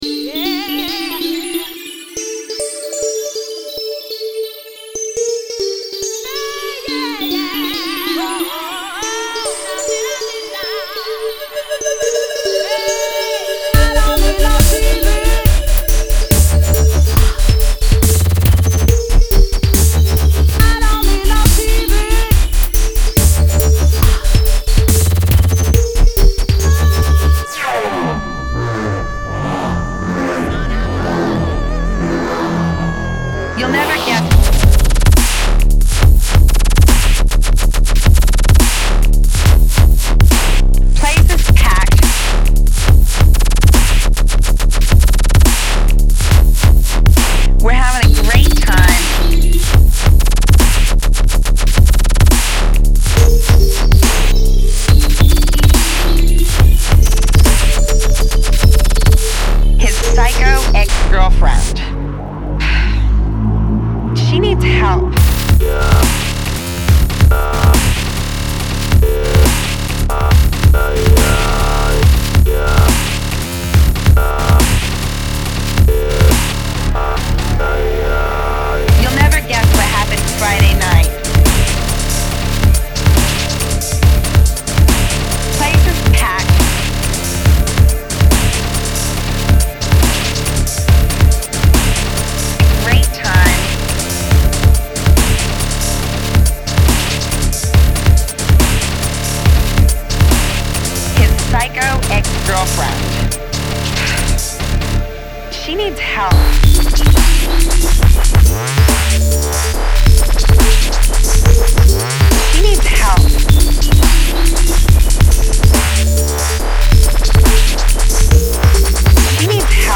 She needs help (Dub step